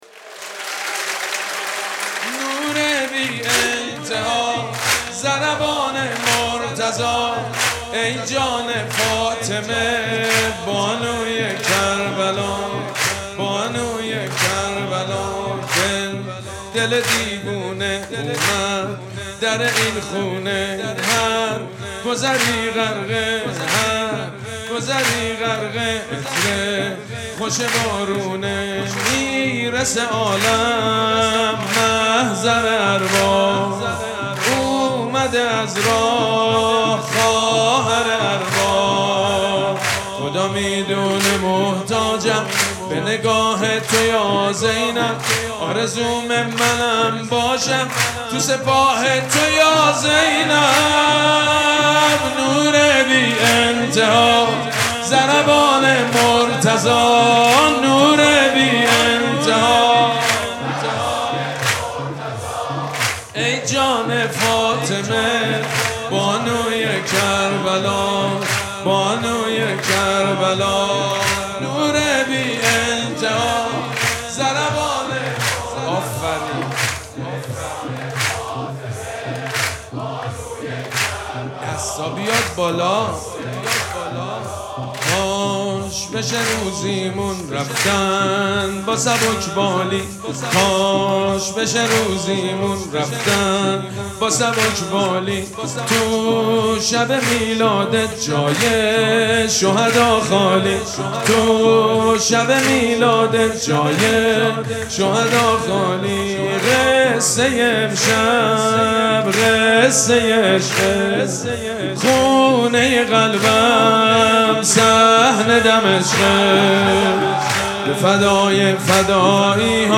مراسم جشن ولادت حضرت زینب سلام‌الله‌علیها
حسینیه ریحانه الحسین سلام الله علیها
سرود
حاج سید مجید بنی فاطمه